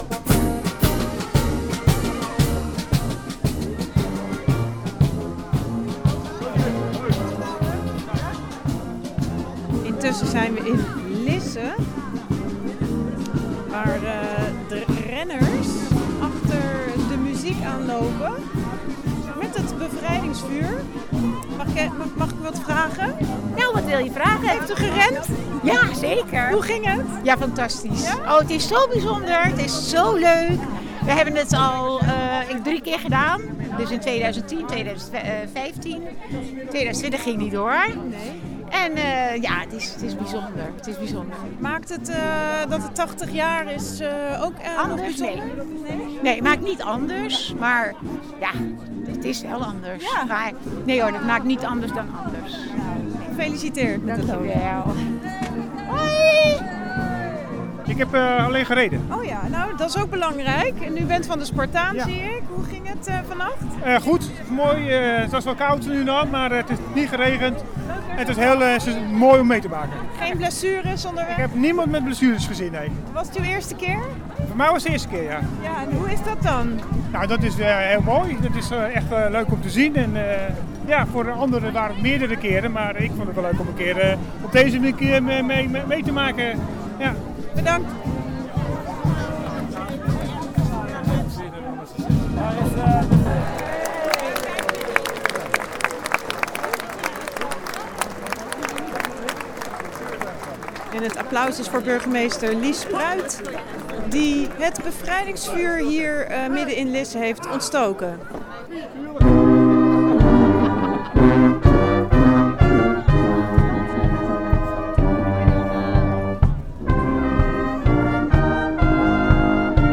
Luister hier naar de reportage met lopers: